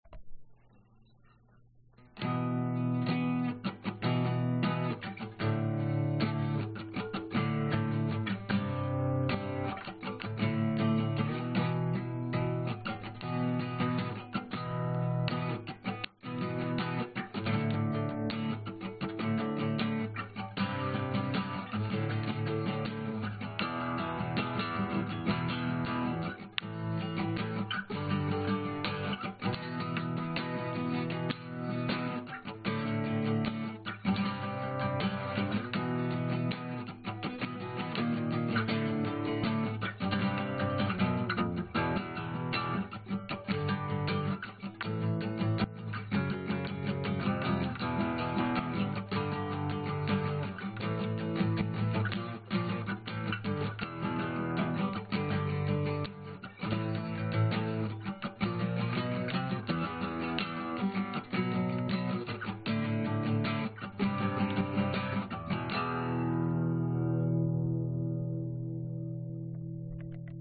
原声重摇滚
描述：一个较重的原声吉他riff。
Tag: 原声 吉他 器乐 摇滚